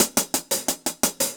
Index of /musicradar/ultimate-hihat-samples/175bpm
UHH_AcoustiHatB_175-05.wav